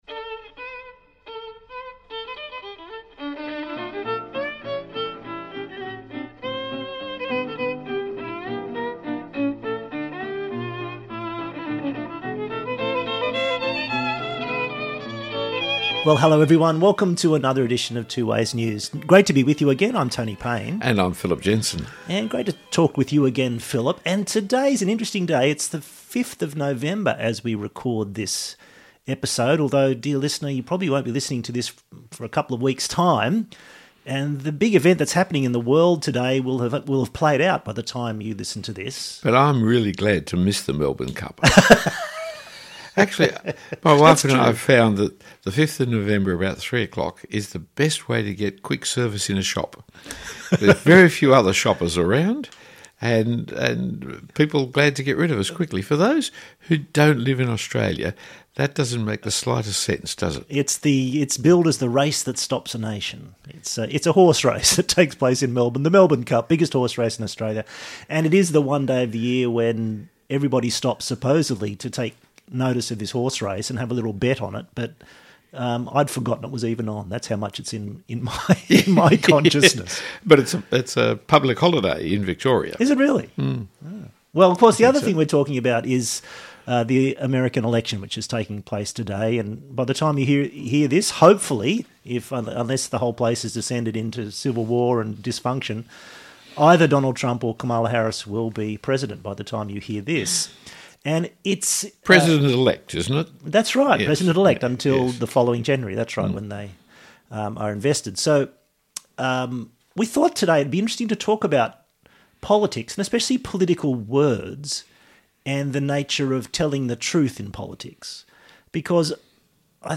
It is a discussion of the nature of words and truth, especially in our politics and public discourse—because the US election campaign exhibited a seeming inability of both candidates to tell the unvarnished, straightforward truth.